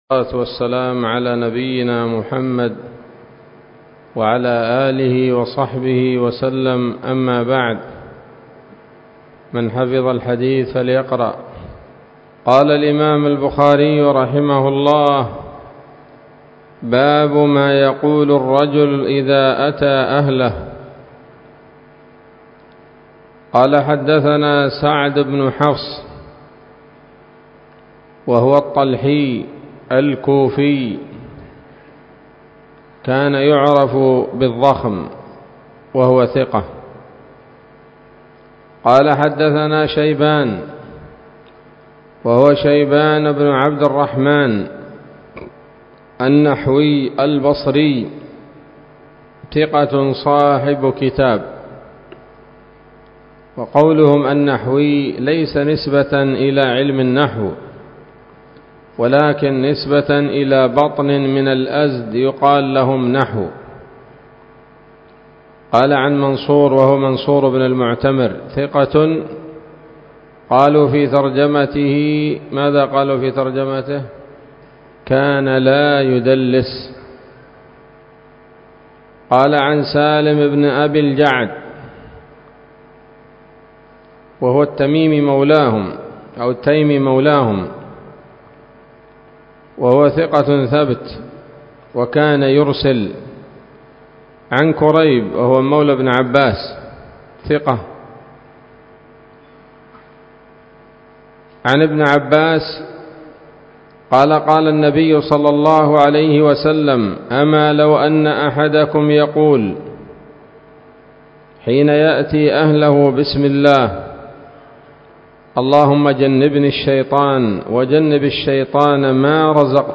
الدرس السادس والخمسون من كتاب النكاح من صحيح الإمام البخاري